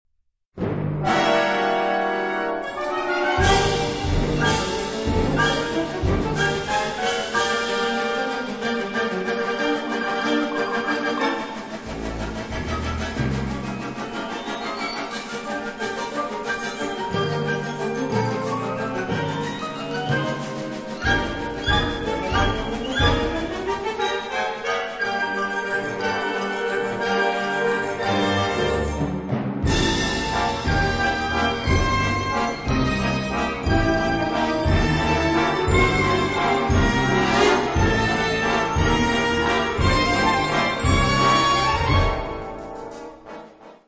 Category Concert/wind/brass band
Subcategory Contemporary Wind Music (1945-present)
Instrumentation Ha (concert/wind band)